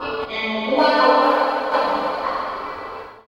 64 GUIT 5 -R.wav